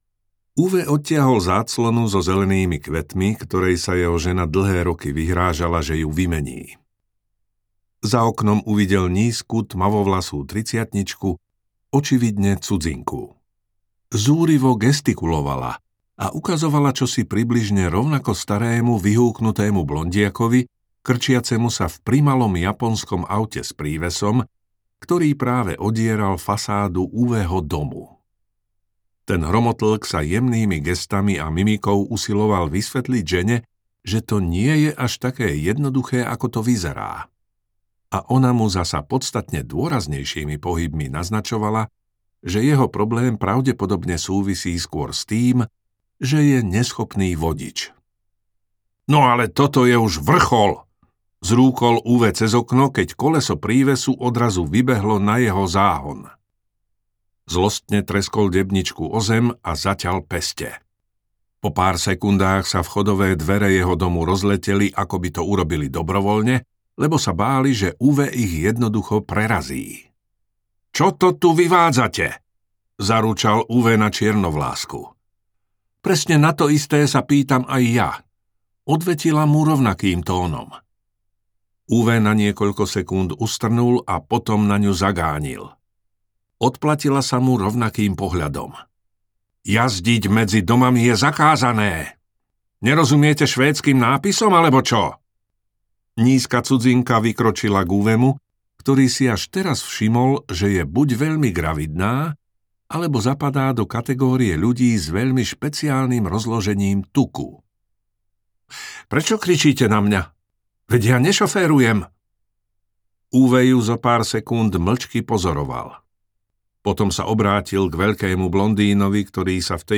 Muž menom Ove audiokniha
Ukázka z knihy
muz-menom-ove-audiokniha